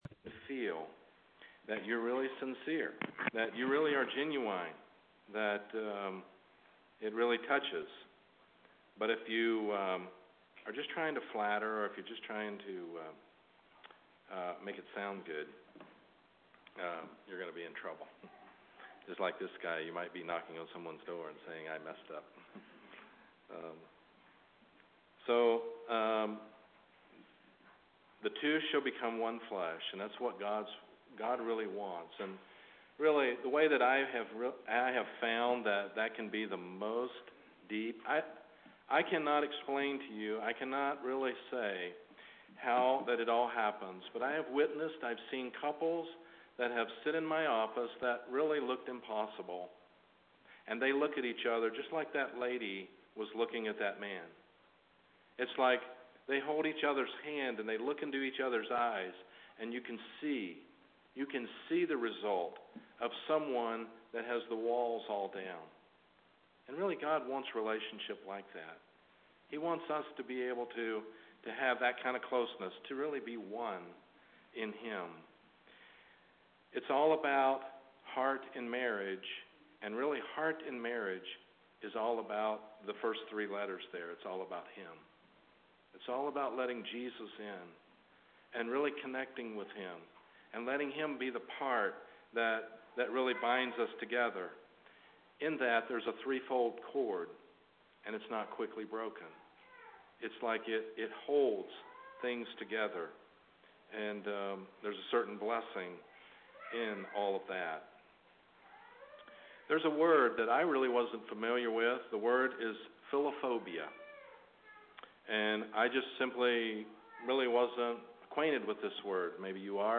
seminar5.mp3